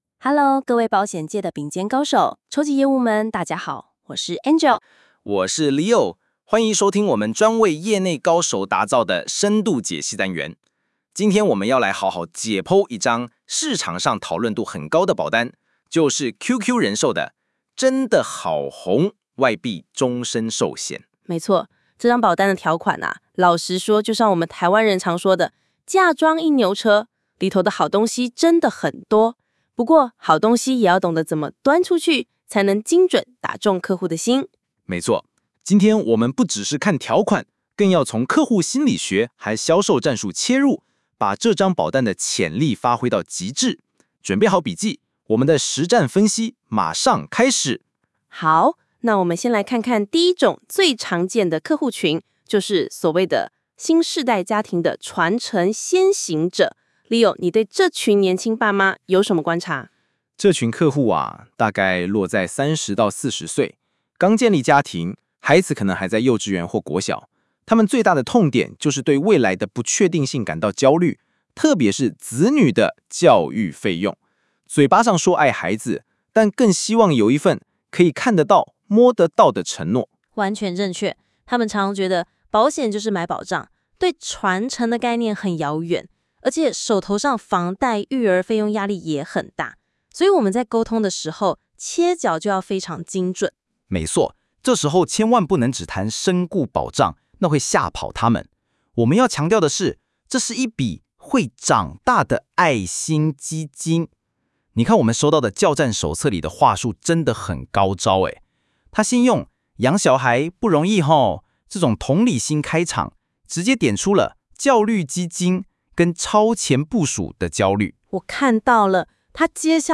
將業務手冊中的精華內容，以對談、故事或案例分享的形式錄製成音頻節目。